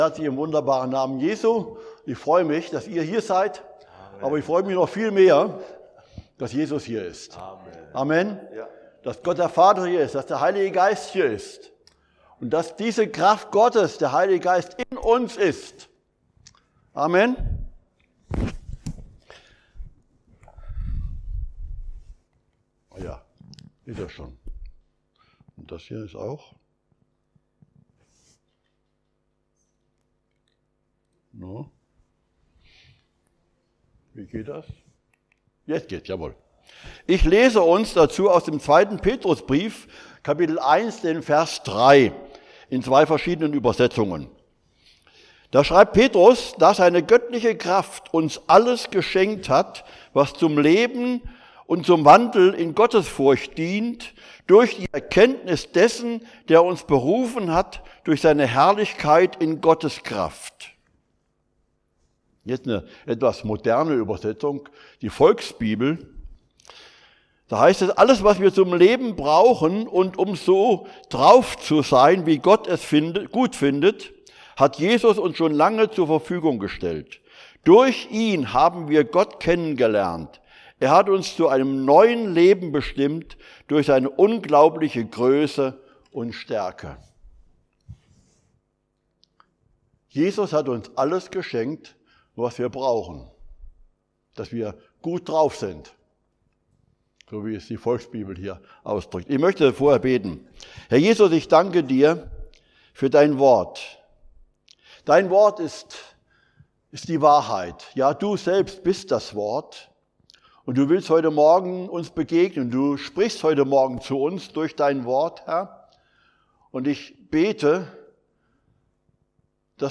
Passage: 2.Petrus 1,3; Joh. 1,26; 1.Thess. 1,5-8; Eph. 6,10; Joh. 16,33; 1.Joh.4,4 Dienstart: Predigt Jesus hat den Feind besiegt!